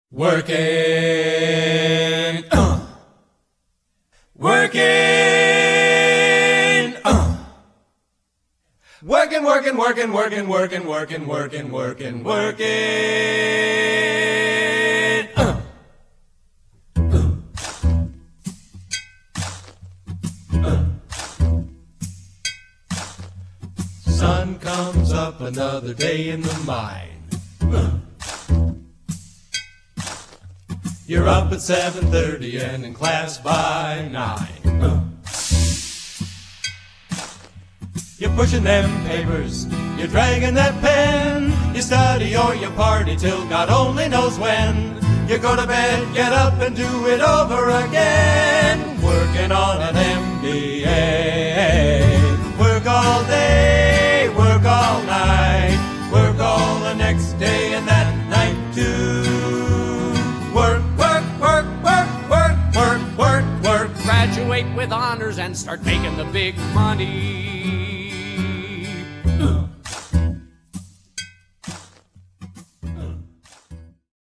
funny folk music